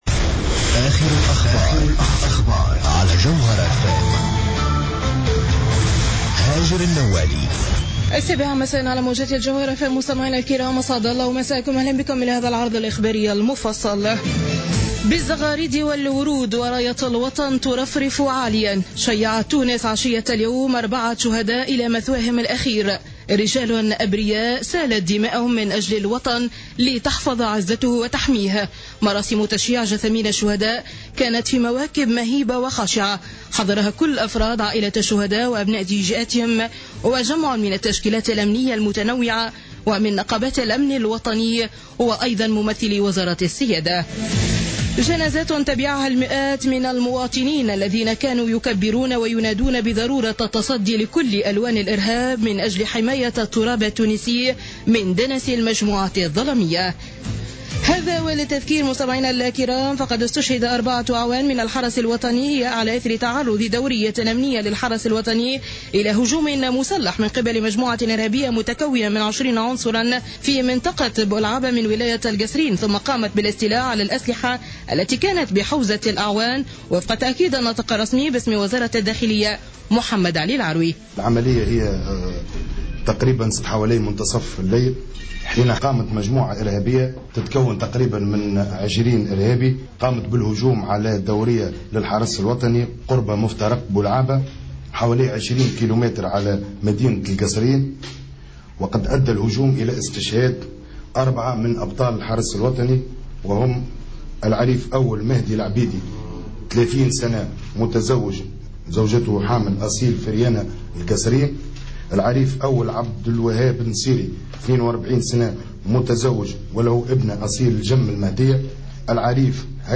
نشرة أخبار السابعة مساء ليوم الاربعاء 18 فيفري 2015